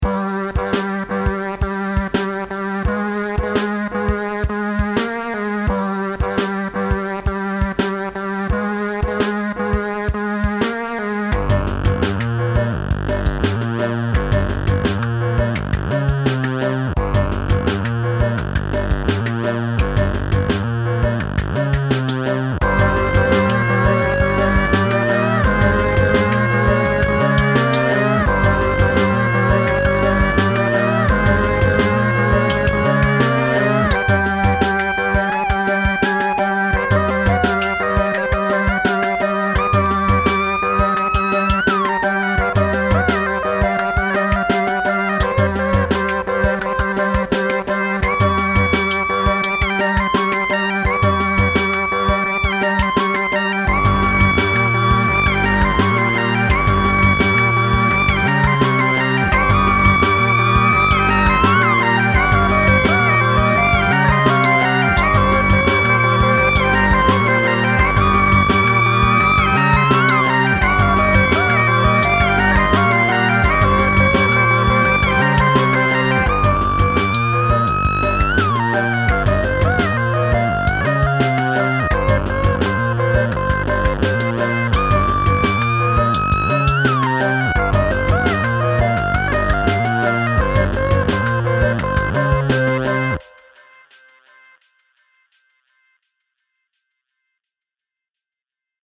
Pieza de rock electrónico
música rock